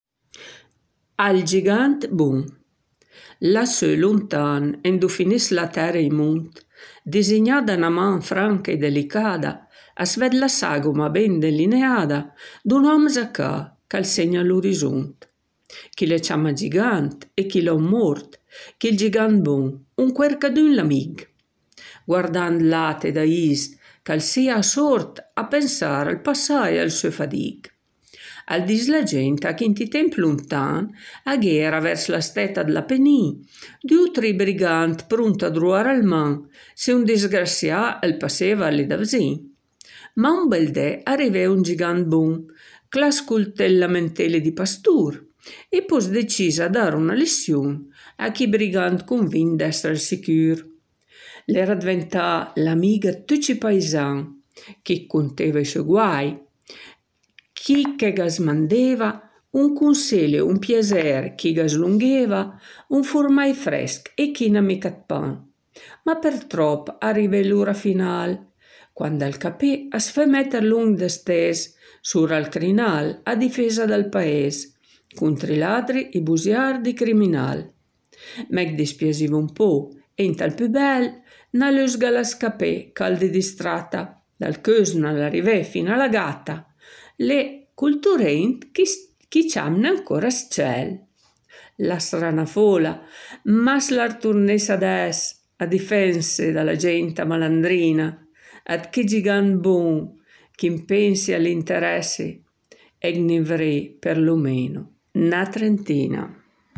La poesia Al Gigânt Bûn è letta